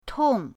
tong4.mp3